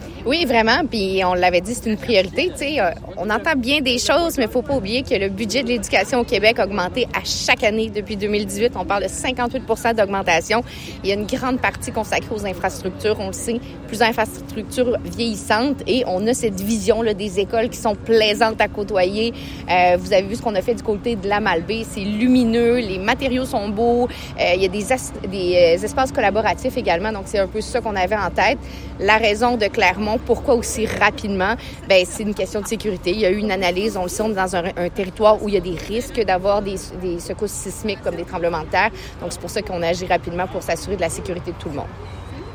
Conférence de presse concernant la construction de la nouvelle école primaire de Clermont.
Kariane Bourassa, députée, Charlevoix–Côte-de-Beaupré